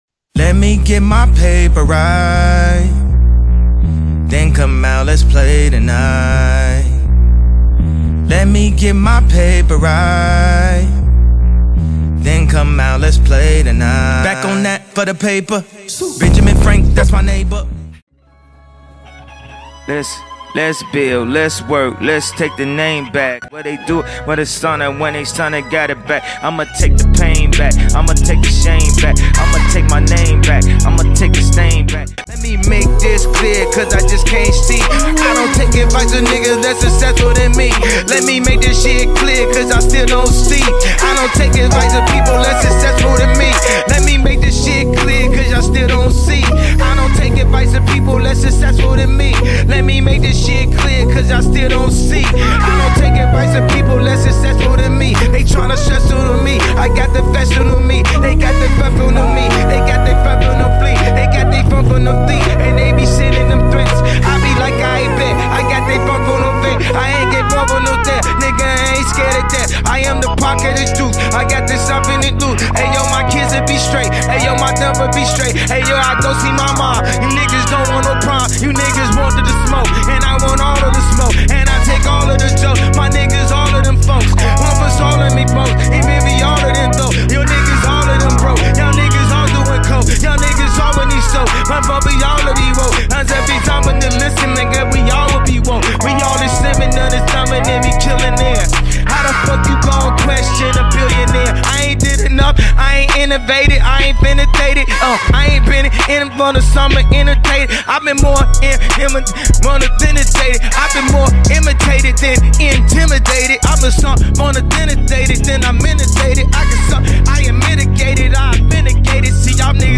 If you don’t mind “rap” music, you can listen to the unofficial “SPC paperwork song” (explicit lyrics):
SPC-paperwork-song.mp3